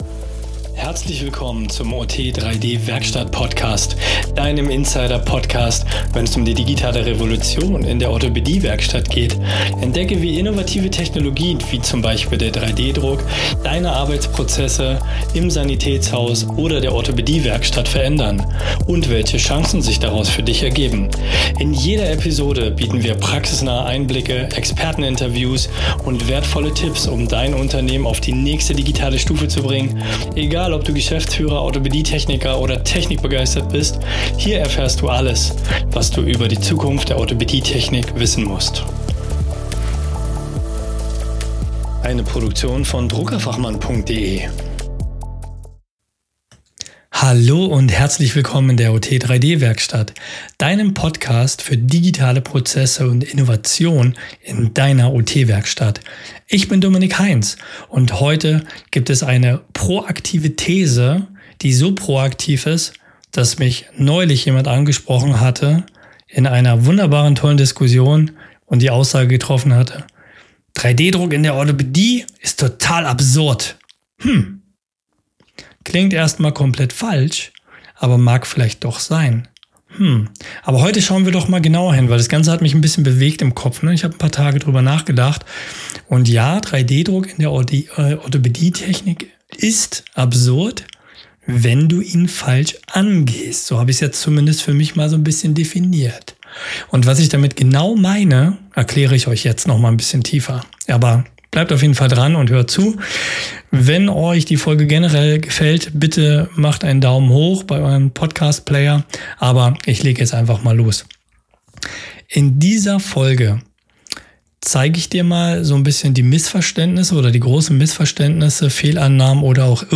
In dieser Solo-Folge spreche ich mit dir ganz offen darüber, warum 3D-Druck in der OT-Werkstatt scheitert, wenn du ihn nur nebenbei laufen lässt – und was du tun kannst, damit aus Frust echte Effizienz wird.